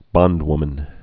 (bŏndwmən)